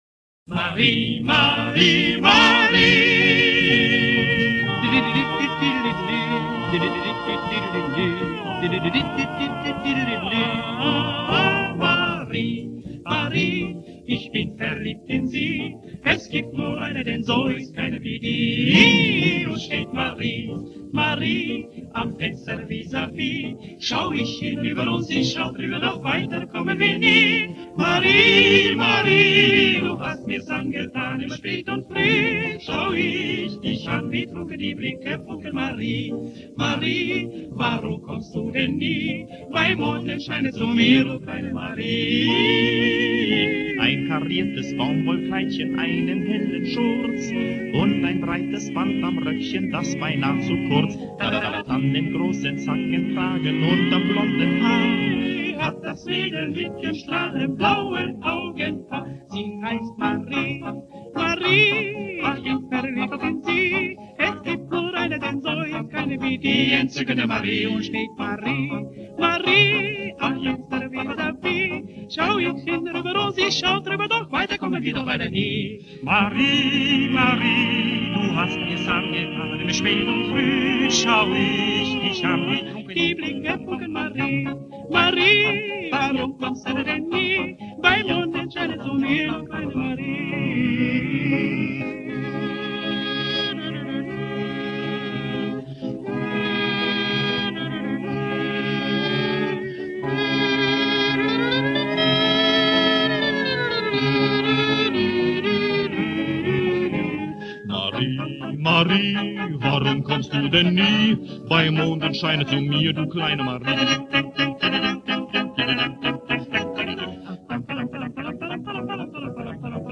немецкая вокальная группа